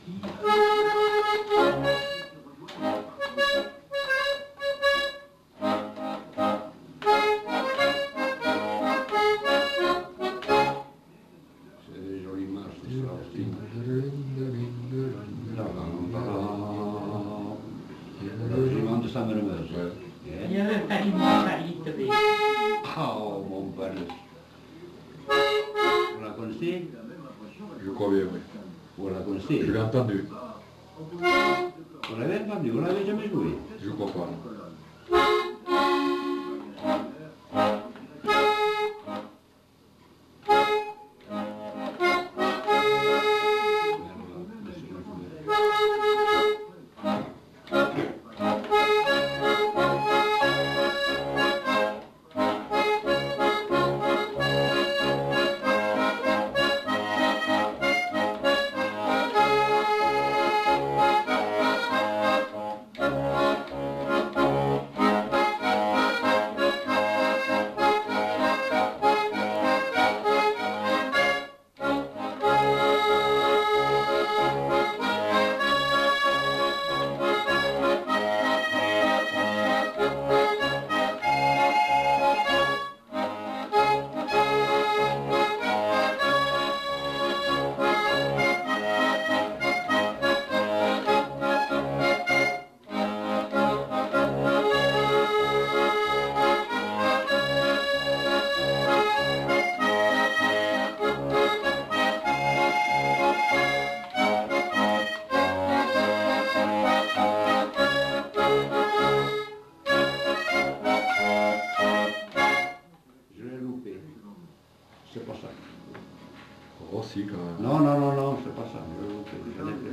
Marche